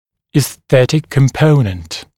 [iːs’θetɪk kəm’pəunənt] [и:с’сэтик кэм’поунэнт] эстетический компонент (US esthetic component)